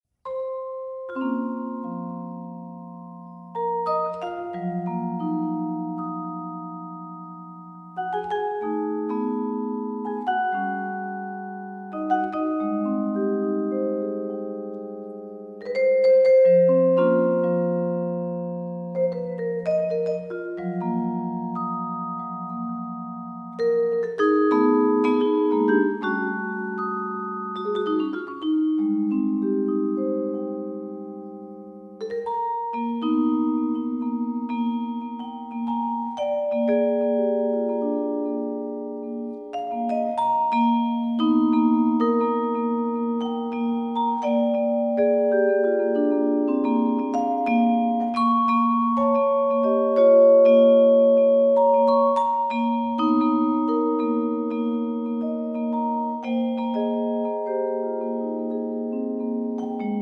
vibraphone